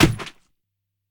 PixelPerfectionCE/assets/minecraft/sounds/item/shield/block2.ogg at ca8d4aeecf25d6a4cc299228cb4a1ef6ff41196e